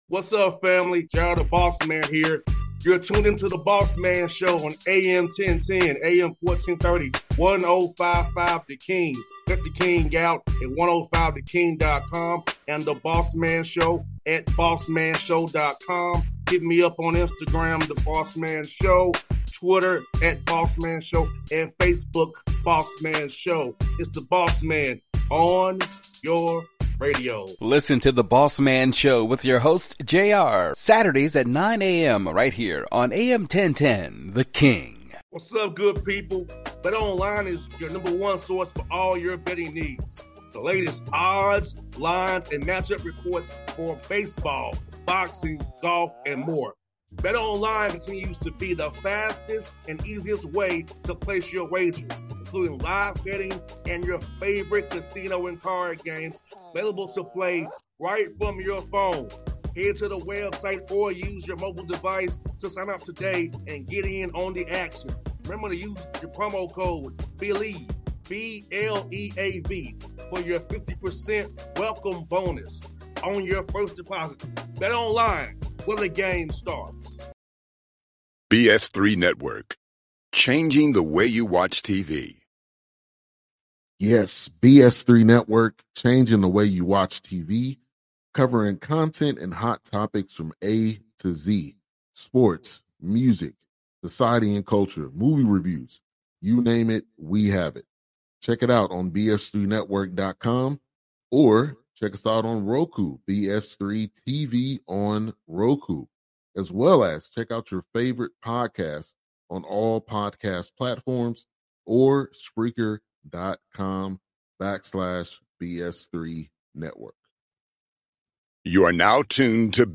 Trent Dilfer Interview